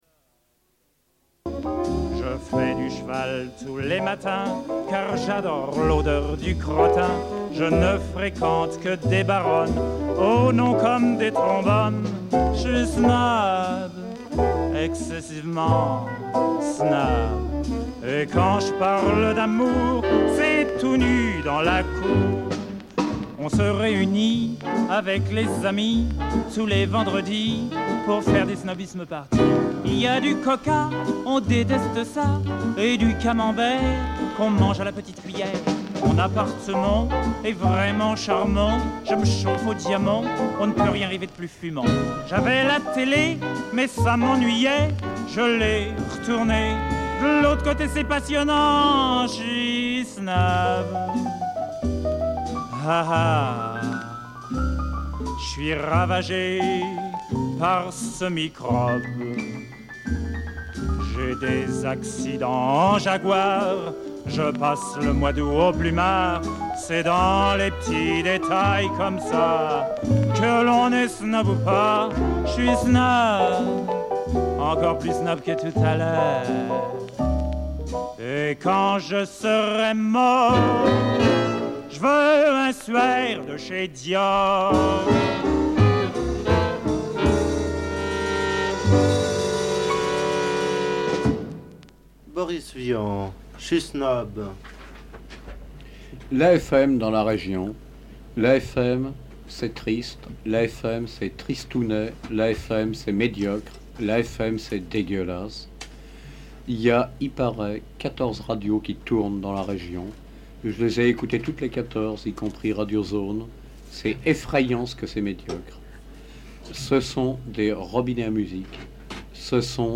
Débat Radio Zones 3/4 - Archives contestataires
Une cassette audio, face A